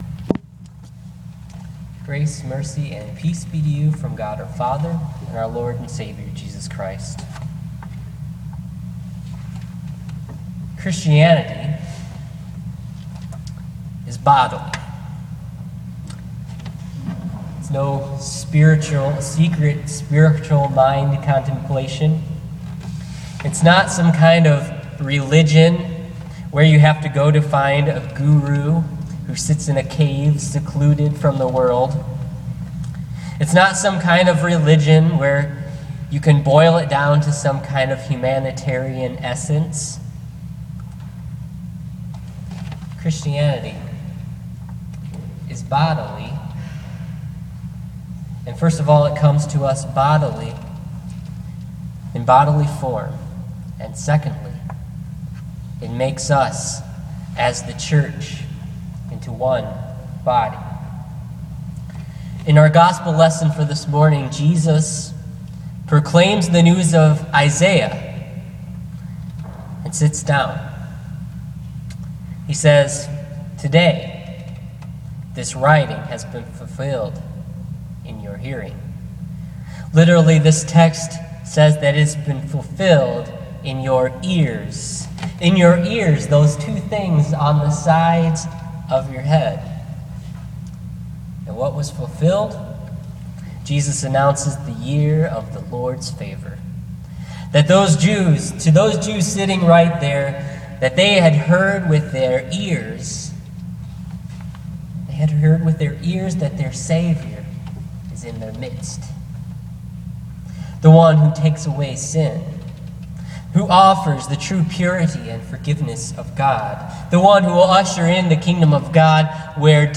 Listen to this week’s sermon on Luke 4:16-30. Our Lord has come into the flesh to redeems us. He has joined us to him and consequently, we are joined to one another.